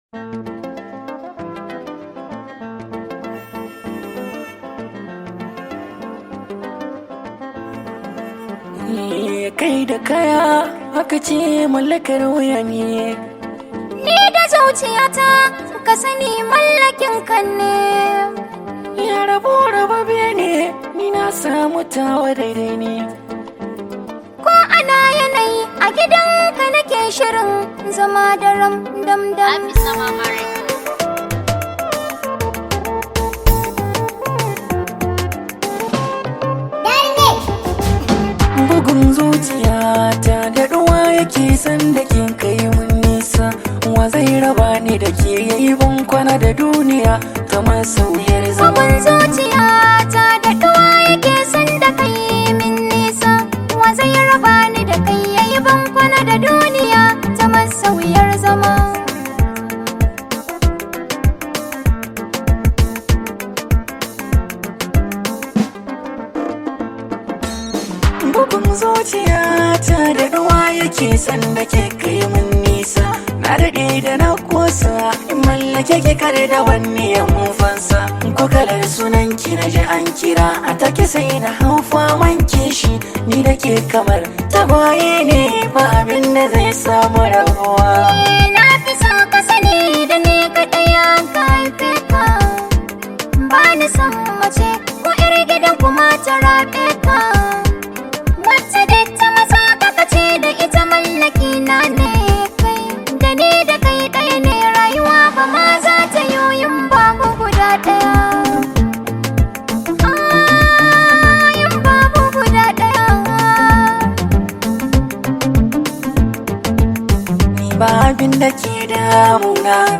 This high vibe hausa song